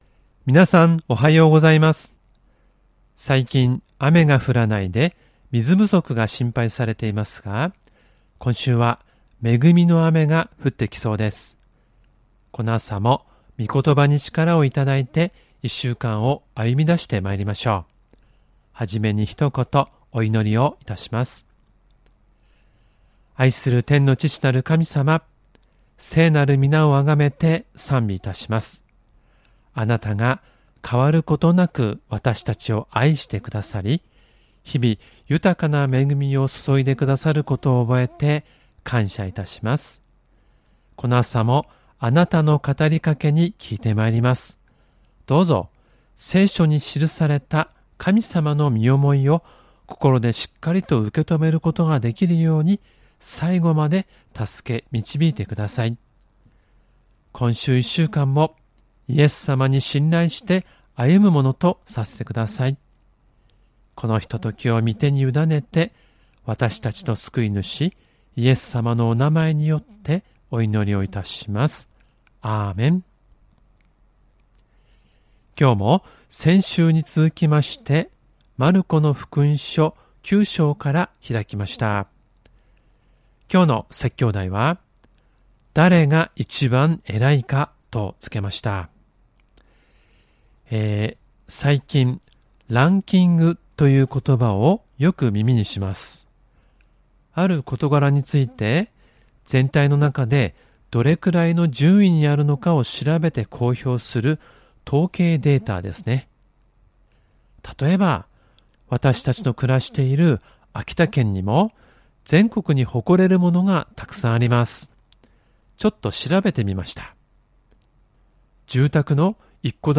●主日礼拝メッセージ（赤文字をクリックするとメッセージが聴けます。MP3ファイル）